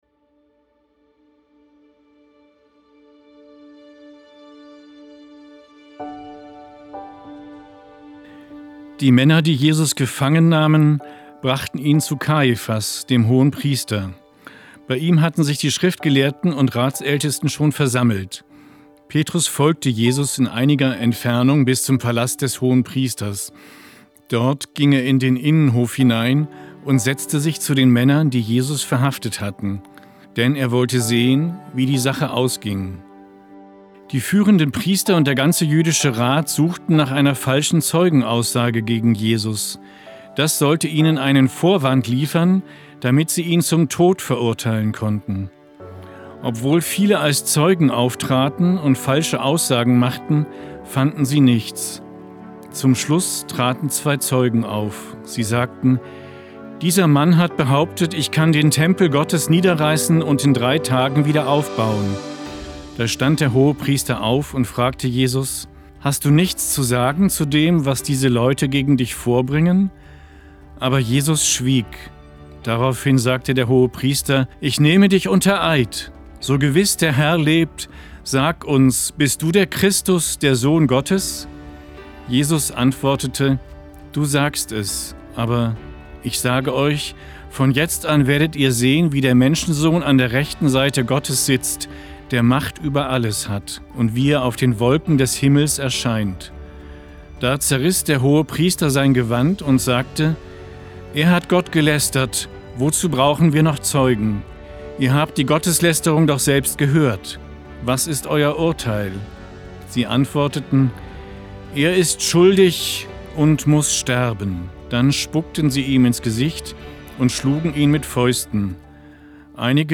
Der Evangelische Kirchenfunk Niedersachsen (ekn) hat die Aufnahmen mit ihm produziert und musikalisch unterlegt.